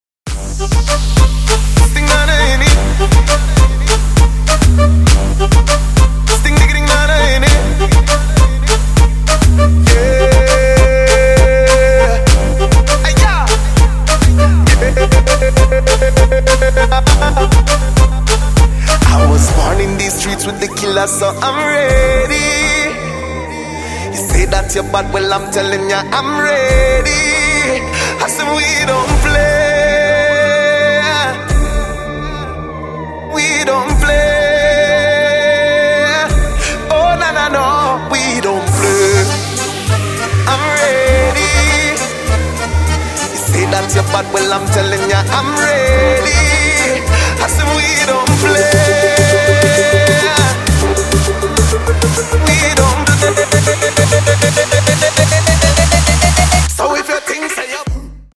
• Качество: 128, Stereo
мужской вокал
dancehall
Trap
регги